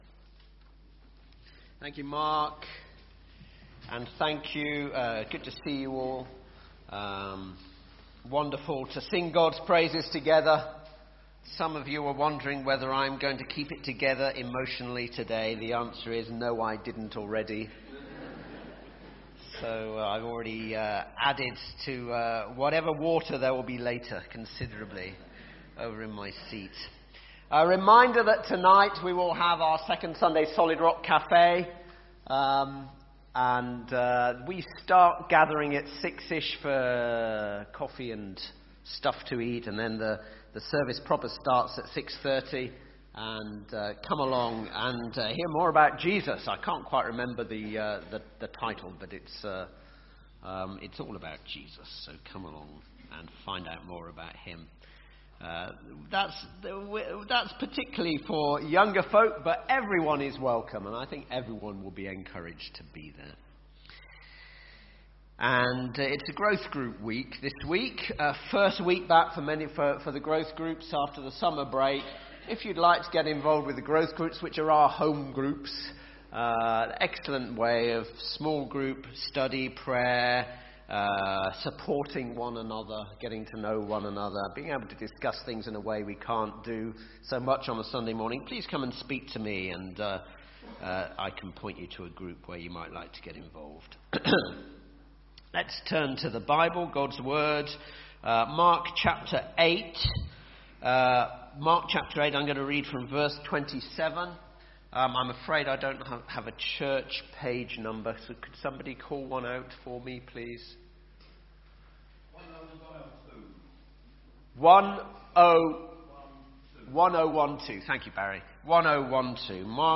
Baptismal Service – September 2019